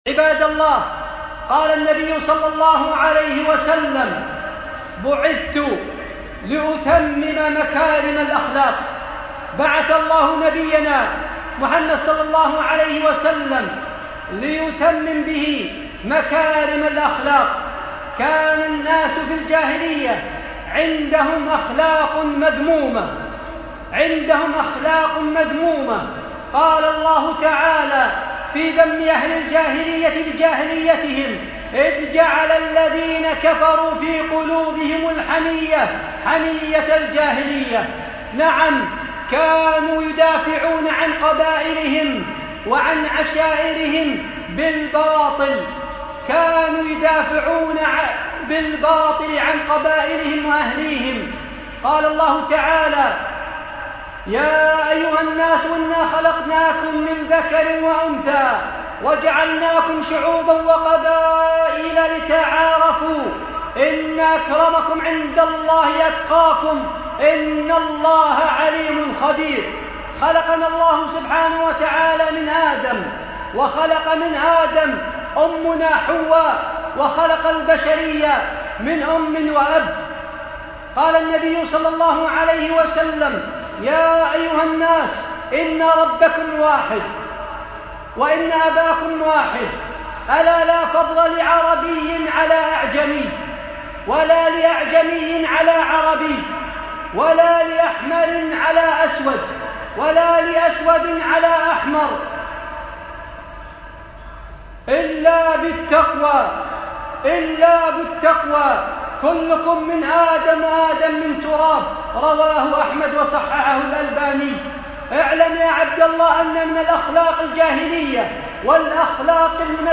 خطبة - ذم عادات الجاهلية الطعن في الانساب والتفاخر بالاحساب